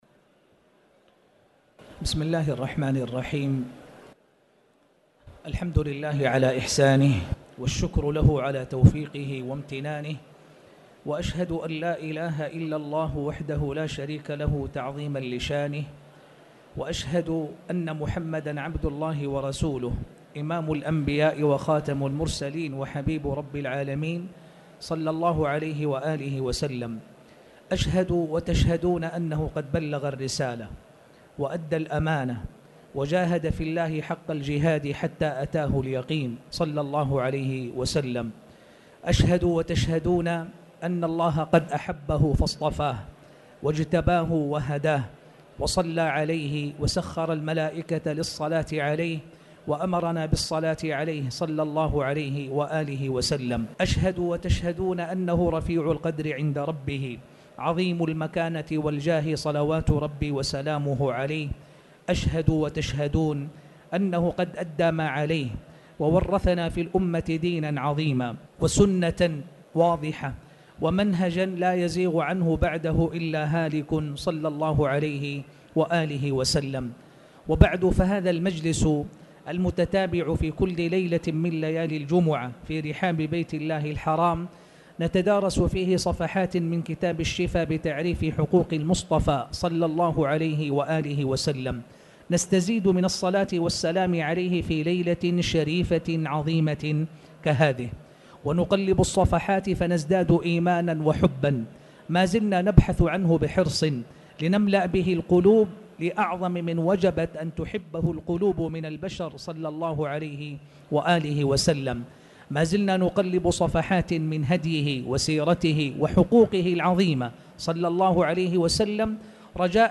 تاريخ النشر ٣ ربيع الثاني ١٤٣٩ هـ المكان: المسجد الحرام الشيخ